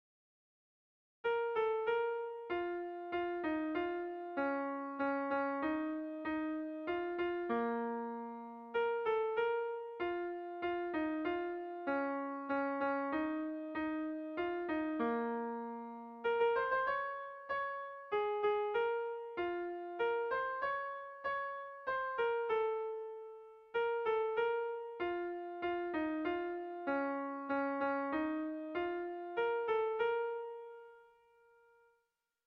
Irrizkoa
Zortziko ertaina (hg) / Lau puntuko ertaina (ip)
A1A2BA1